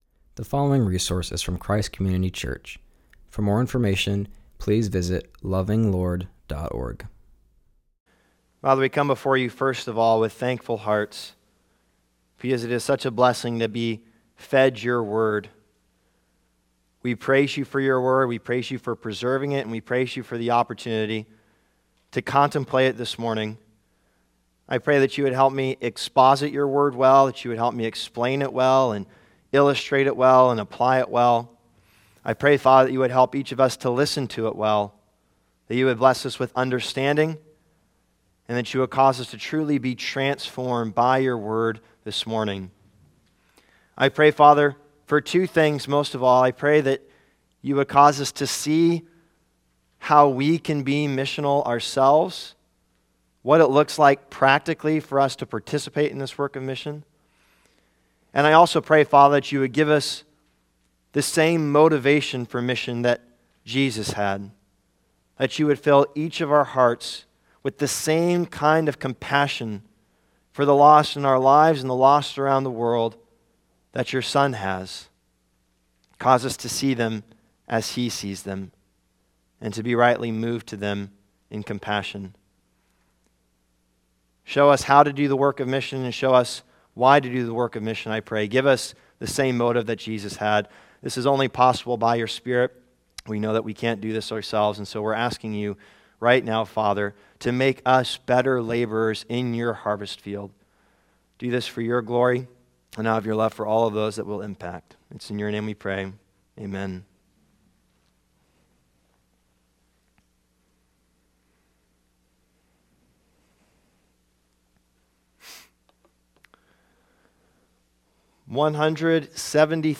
continues our series by preaching on Matthew 9:35-38.